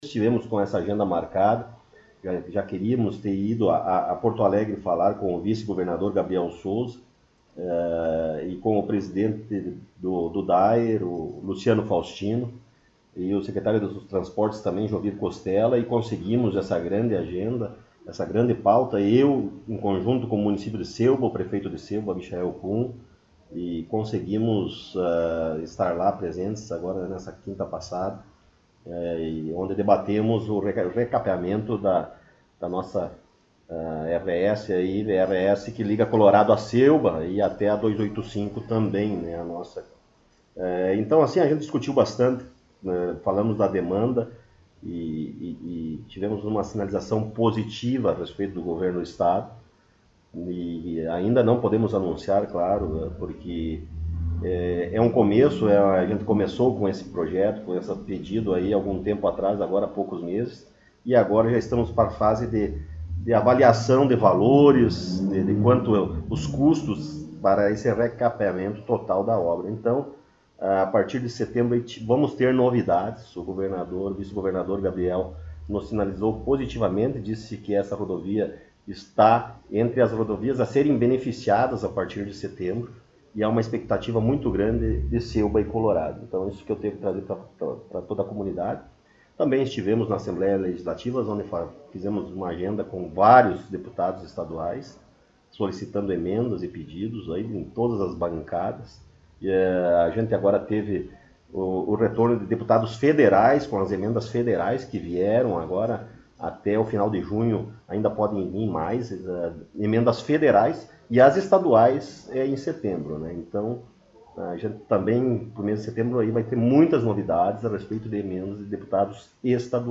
Prefeito Rodrigo Sartori concedeu entrevista